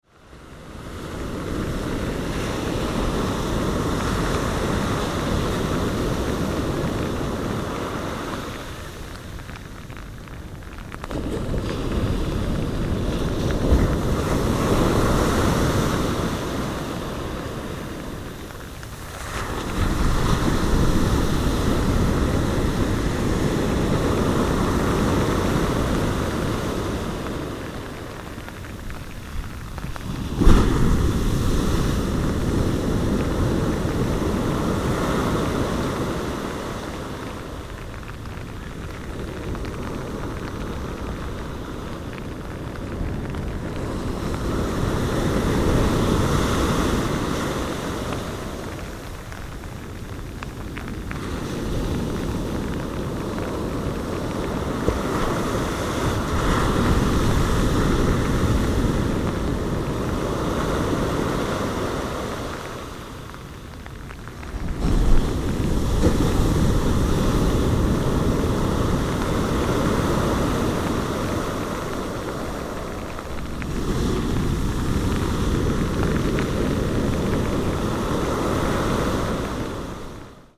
バイノーラル録音とは、簡単に言えばヘッドホン向けに処理された音声だそうです。
蛙の鳴き声
BeachRain.mp3